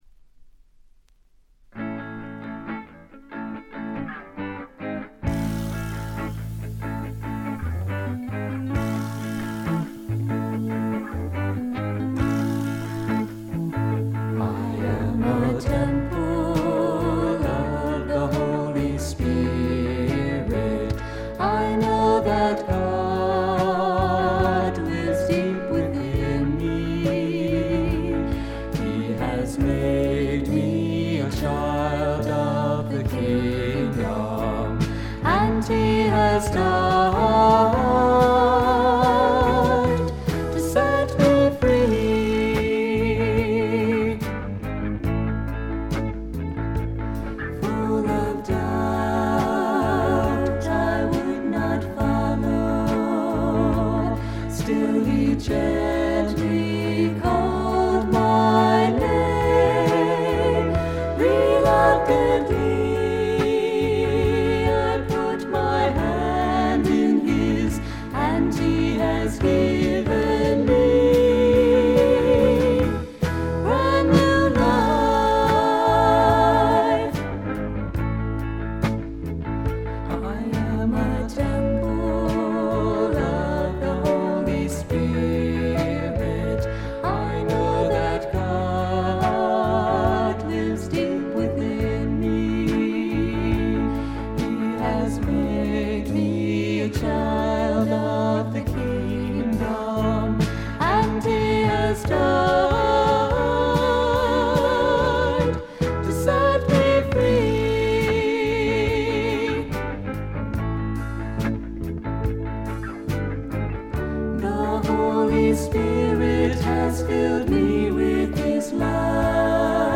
ほとんどノイズ感無し。
全体に涼やかでメロウな味わいがたいへんに美味なもので、この音で好事家達が見逃すわけがありませんね。
試聴曲は現品からの取り込み音源です。